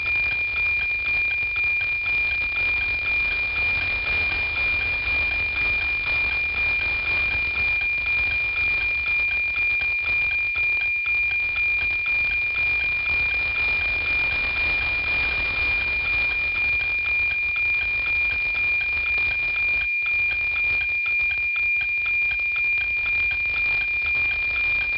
With my setup, 137 MHz satellite signals fade in and out during each pass, producing pieces of map, but not full maps.
The SDR# image below shows a moderately strong signal from NOAA-15 as received on December 28, 2016.
NOAA-15 Signal (SDR#)
The sliver of map corresponding to the strongest part of the NOAA-15 signal was clear, but during the entire pass, the signal was more out than in.